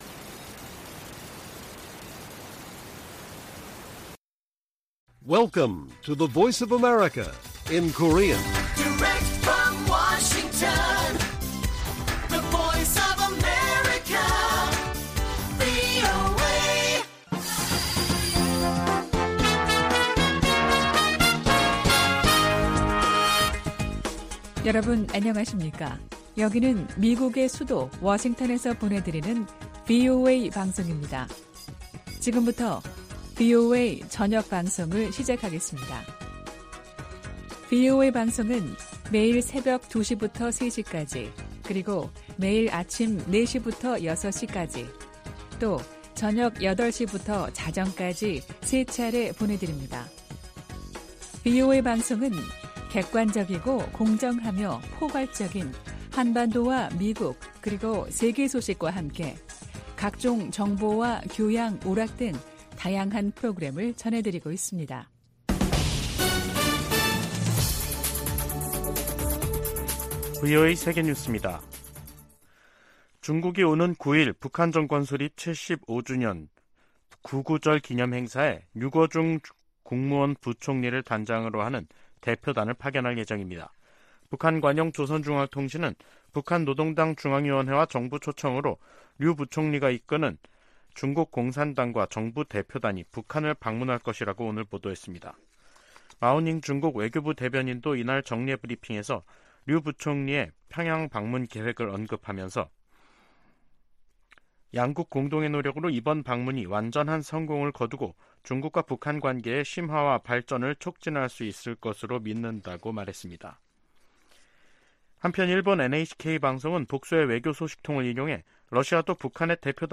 VOA 한국어 간판 뉴스 프로그램 '뉴스 투데이', 2023년 9월 7일 1부 방송입니다. 존 커비 백악관 국가안보회의(NSC) 전략소통조정관은 북한과 러시아 간 무기거래 협상을 면밀히 주시할 것이라고 경고했습니다. 동아시아정상회의(EAS)에 참석한 윤석열 한국 대통령은 대북 제재 준수에 유엔 안보리 상임이사국의 책임이 더 무겁다고 말했습니다. 유럽연합(EU)은 북러 정상회담 가능성에 관해 러시아의 절박한 처지를 보여줄 뿐이라고 지적했습니다.